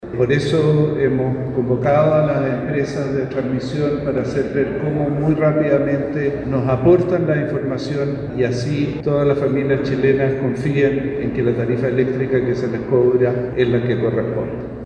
Por ello, el ministro de dicha cartera, Álvaro García, dijo previamente que las empresas deben hacer el mayor esfuerzo por entregar esta información, o arriesgan multas muy significativas.